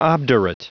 Prononciation du mot obdurate en anglais (fichier audio)
Prononciation du mot : obdurate